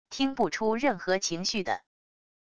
听不出任何情绪的wav音频